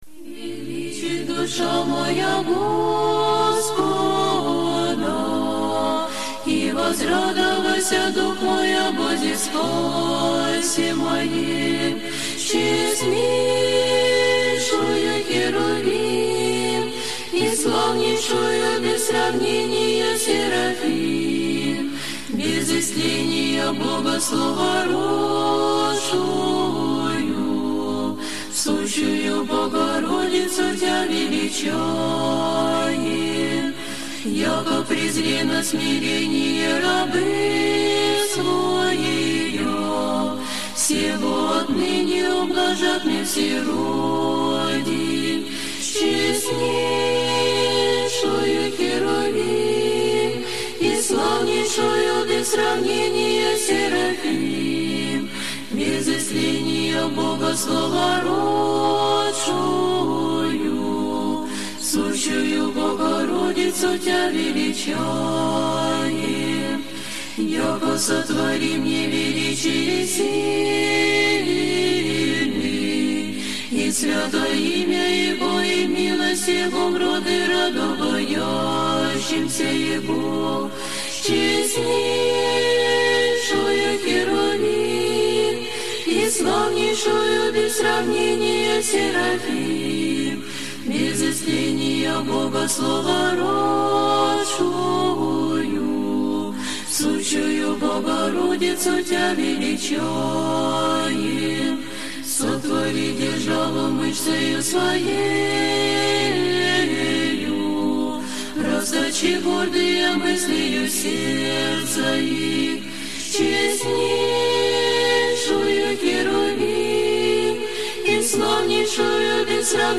Прослушивание аудиозаписи Песни Пресвятой Богородицы на церковнославянском языке в исполнении храма Успения Пресвятой Богородицы.